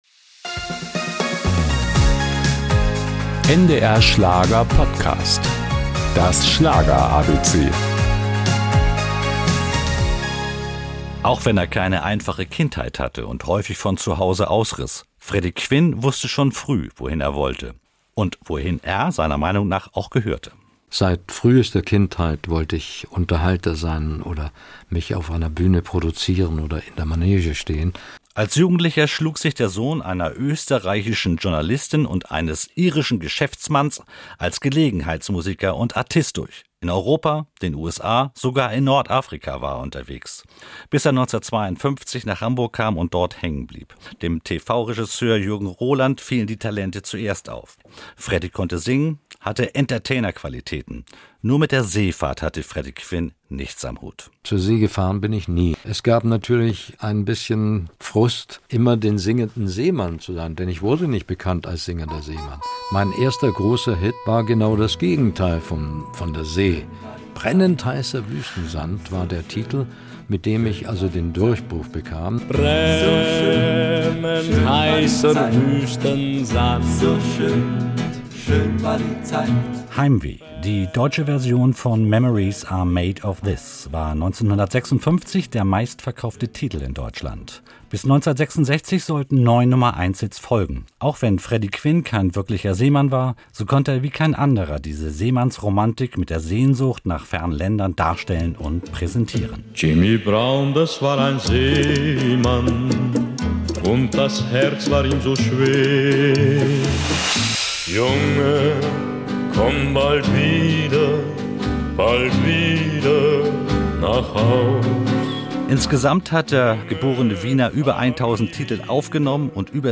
Dazu gibt es O-Töne des Stars und Auszüge aus seiner Musik.